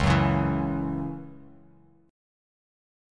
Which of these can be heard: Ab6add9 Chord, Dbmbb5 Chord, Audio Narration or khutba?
Dbmbb5 Chord